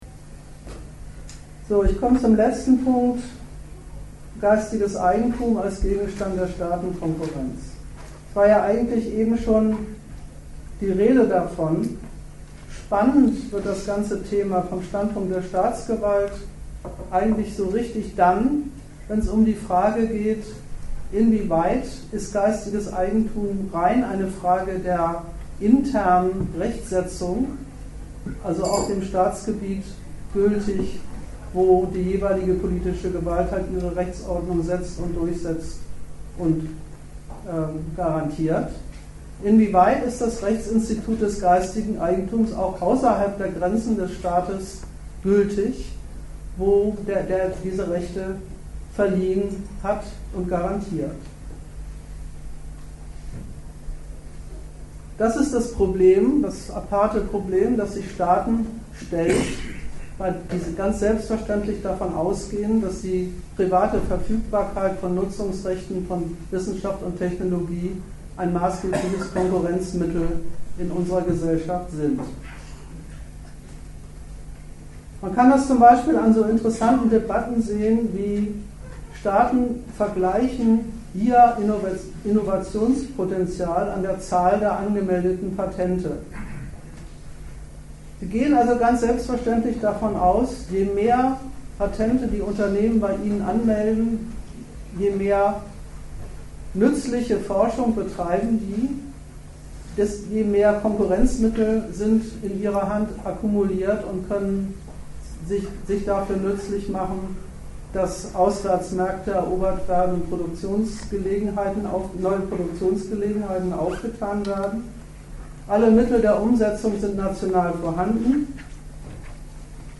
Dozent Gastreferenten der Zeitschrift GegenStandpunkt